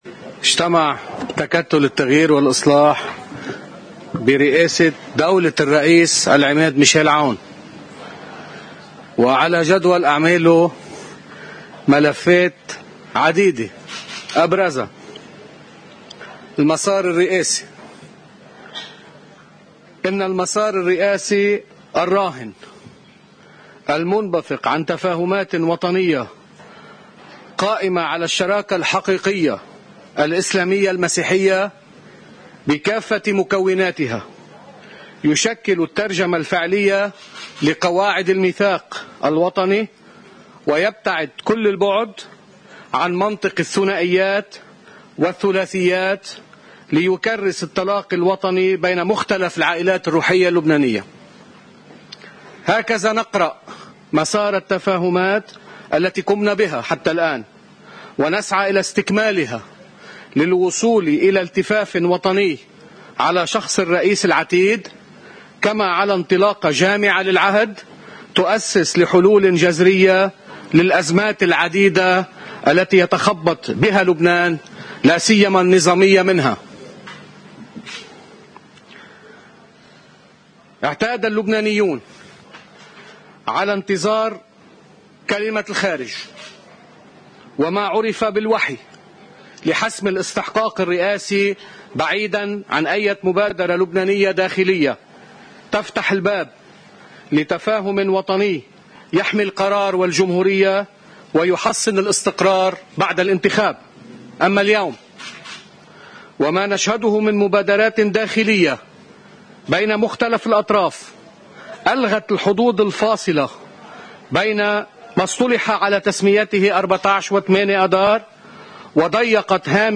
كنعان بعد إجتماع تكتّل التغيير والإصلاح: